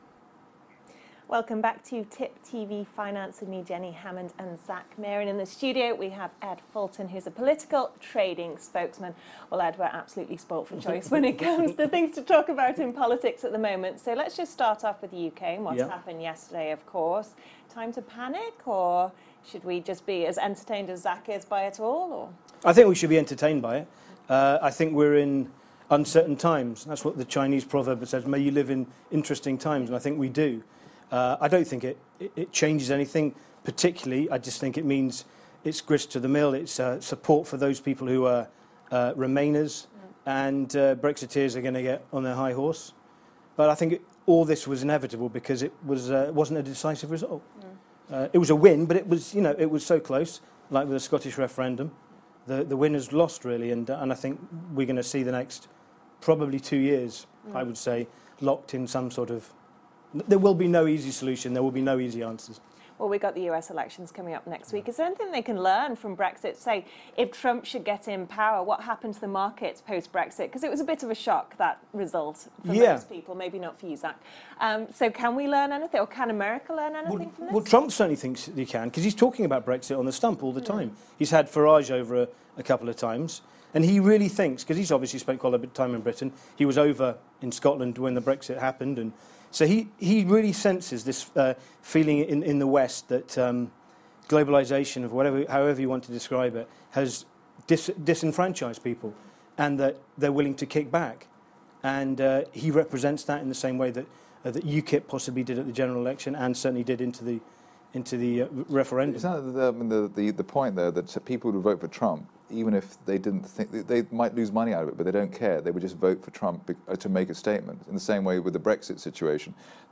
in the Tip TV Studio